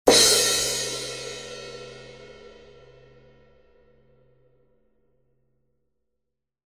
Zildjian 17 A Custom Fast Crash Cymbal is bright, airy and responsive. This cymbal crashes with an extremely short decay.